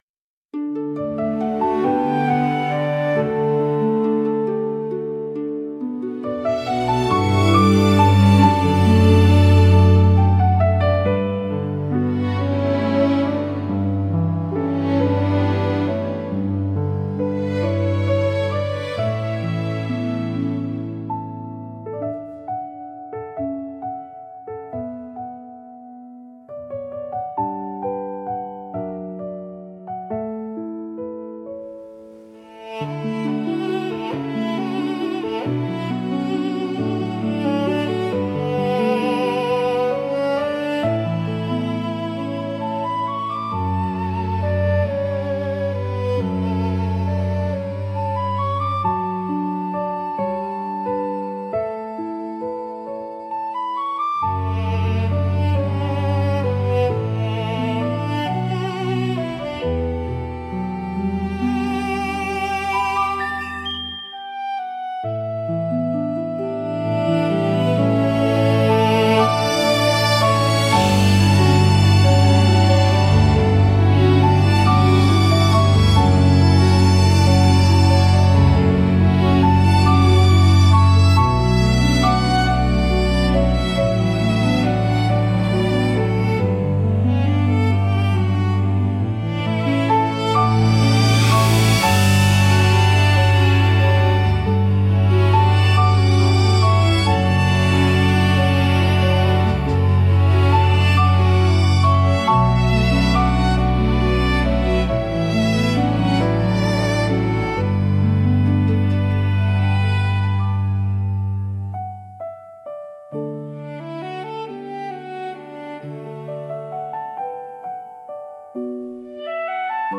オリジナルの朝ジャンルは、しっとりと穏やかでありながら希望に満ちた明るい曲調が特徴です。
優しいメロディと柔らかなアレンジが一日の始まりを穏やかに包み込み、聴く人に前向きな気持ちをもたらします。
静かで清々しい空気感を演出しつつ、心に明るい希望や期待を芽生えさせる効果があります。